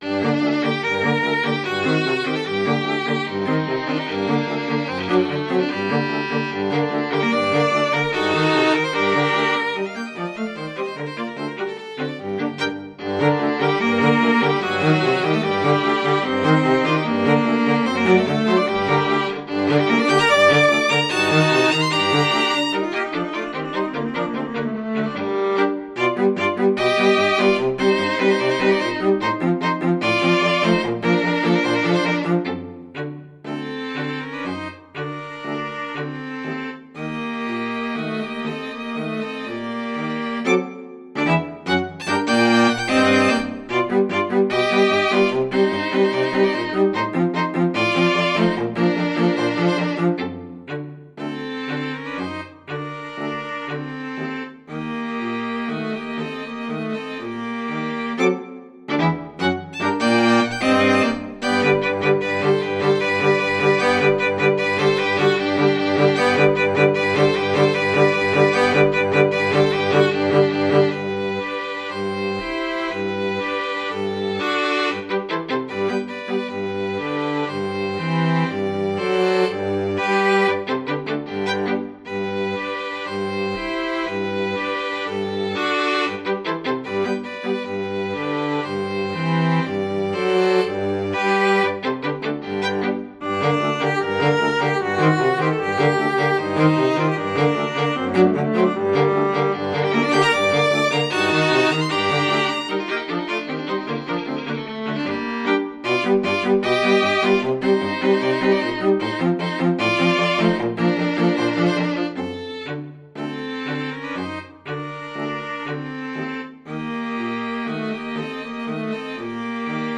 for String Quartet
Voicing: String Quartet